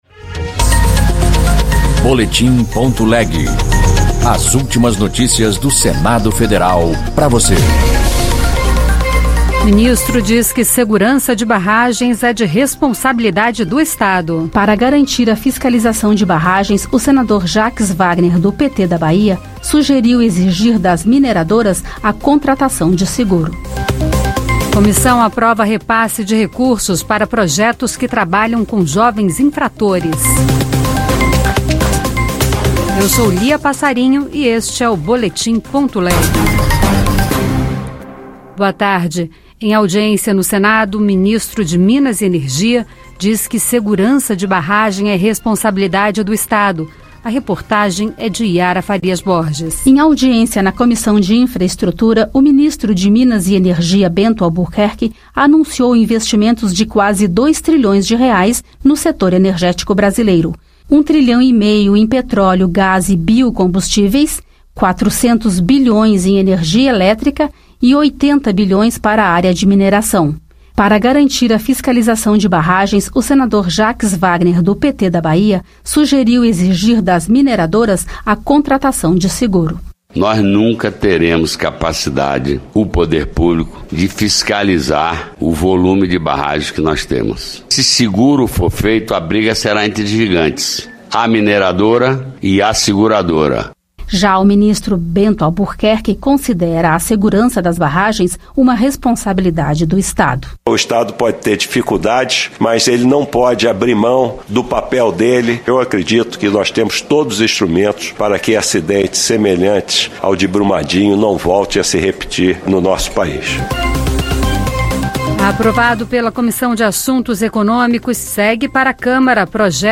Notícias do Senado Federal em duas edições diárias